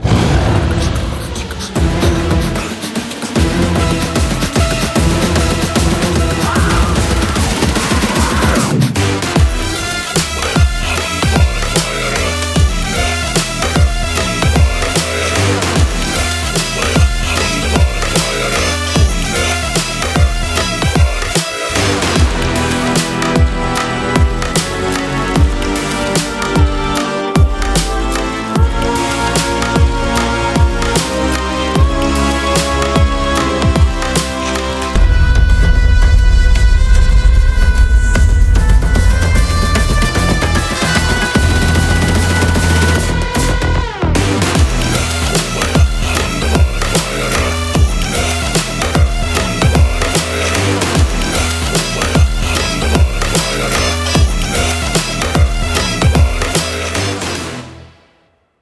without any dialogues and disturbances